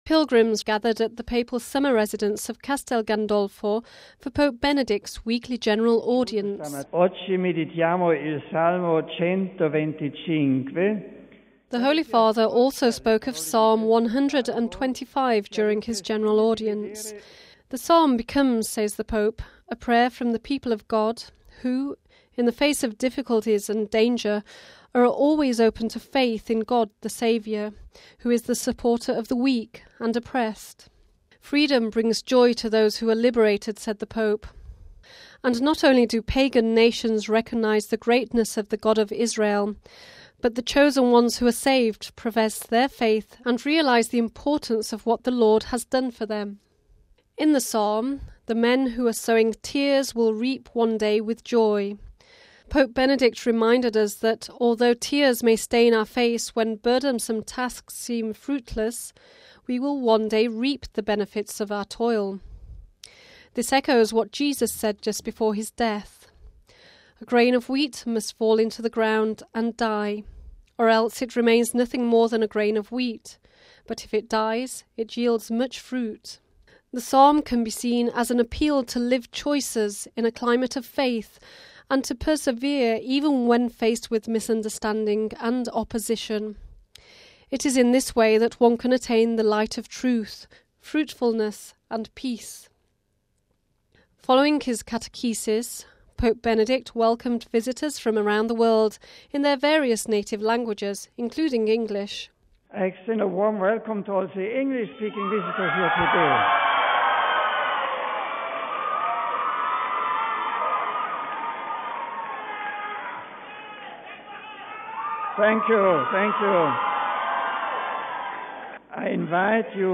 (17 August 2005 - RV) On the eve of Pope Benedict’s visit to Germany the Holy Father has greeted pilgrims and visitors who have travelled to Rome for his weekly general audience. Speaking from his summer residence just south of Rome the Holy Father reminded the faithful that God is always by our side – even in times of trial.